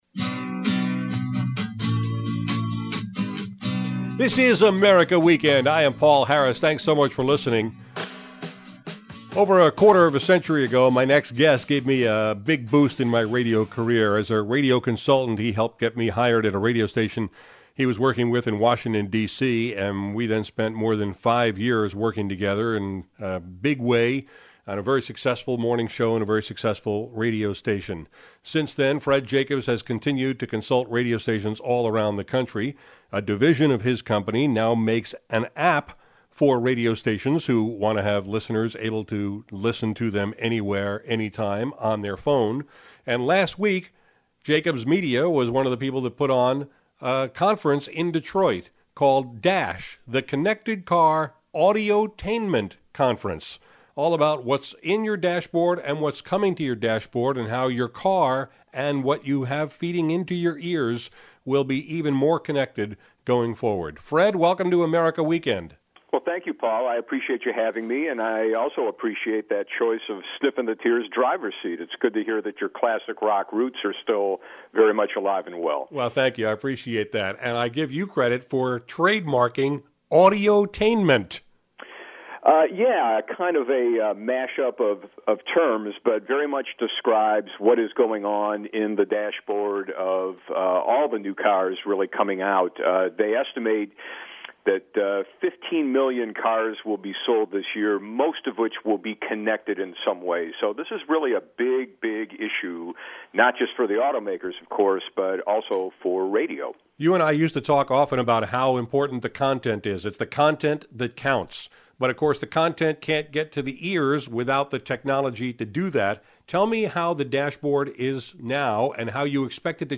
He joined me on America Weekend to explain how the hardware in the car is changing to give you more content options at the touch of a button — a revolution that radio providers have to understand or be left behind. We discussed the safety issues of in-dash screens, getting used to our cars talking back to us, and why the car companies aren’t developing common standards for the interfaces.